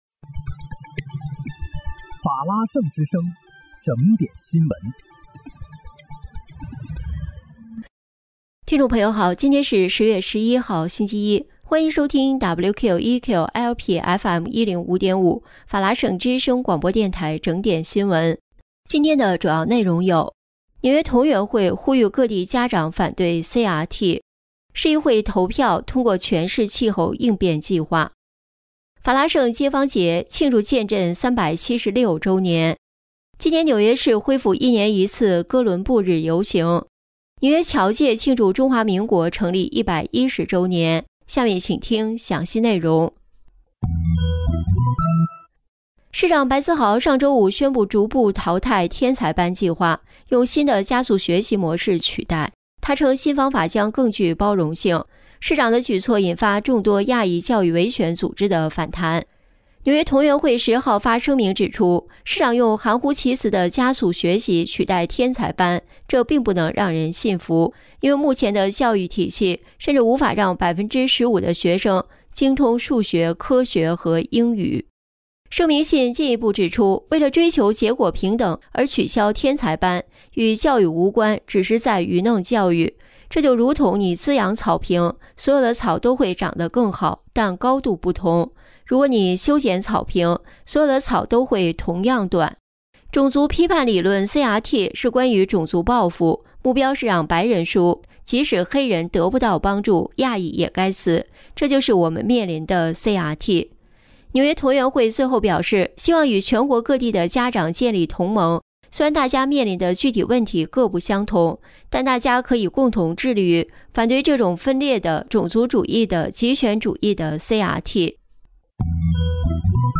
10月11日（星期一）纽约整点新闻